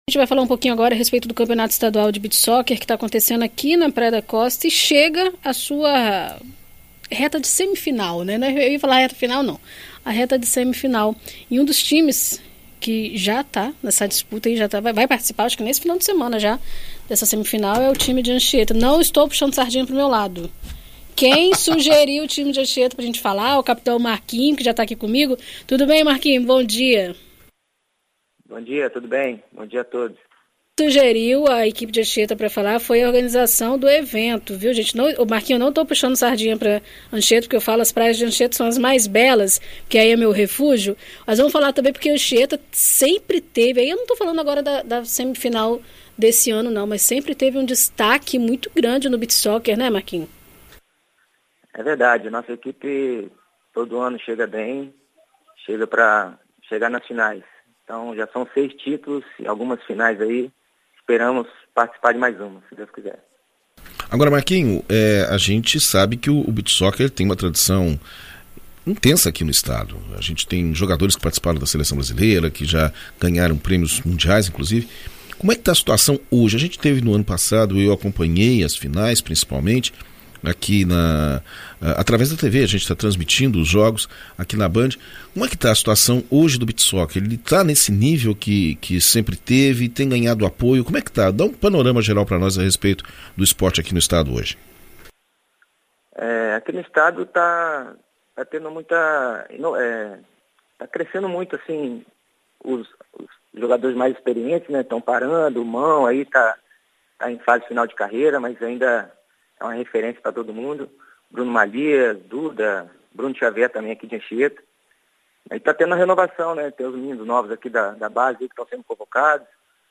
Em entrevista à BandNews FM Espírito Santo